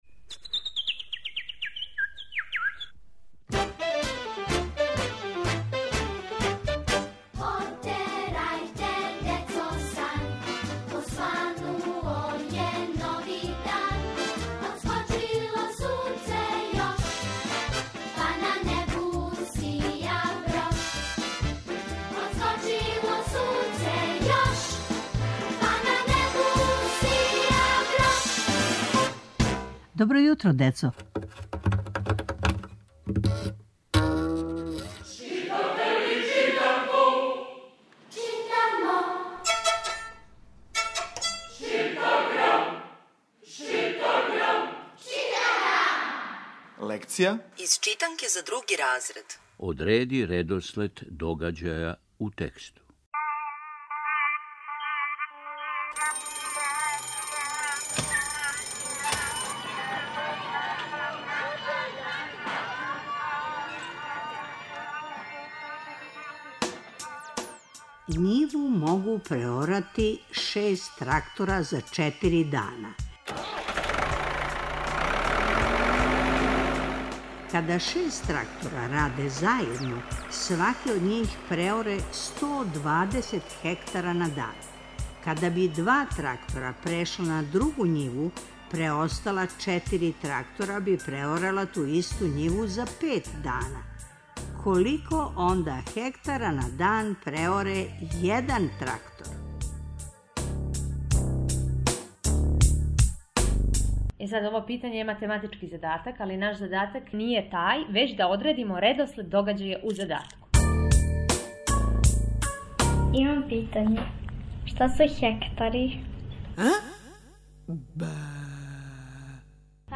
Сваког понедељка у емисији Добро јутро, децо - ЧИТАГРАМ: Читанка за слушање. Ове недеље - други разред, лекција: Одреди редослед догађаја у тексту.